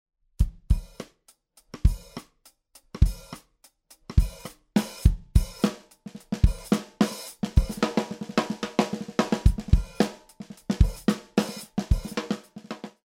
シルバーフィニッシュ、BLUE/OLIVE バッジを施したLudwigアクロライトシリーズ。Ludwigのエントリーモデルとして1960年代から発売が開始され、アルミシェルならではのやわらかく軽快なサウンドで現在では幅広く使用されています。